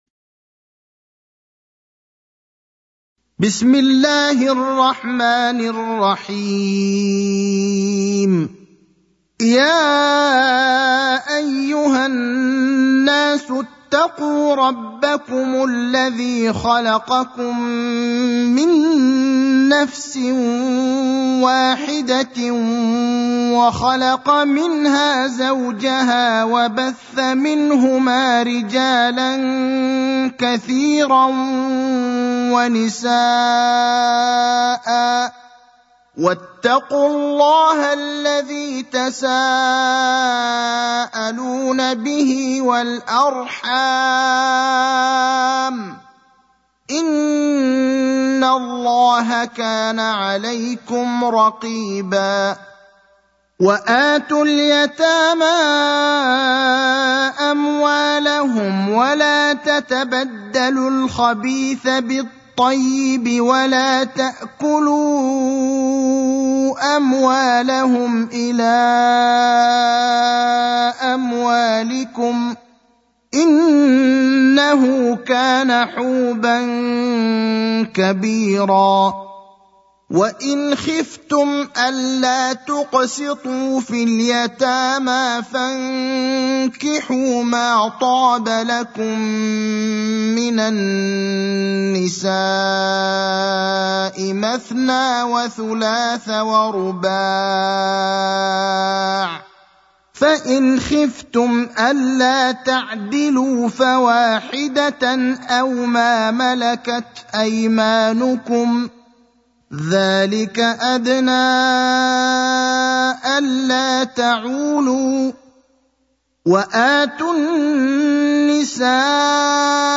المكان: المسجد النبوي الشيخ: فضيلة الشيخ إبراهيم الأخضر فضيلة الشيخ إبراهيم الأخضر النساء (4)